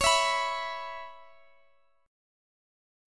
Listen to C#m7 strummed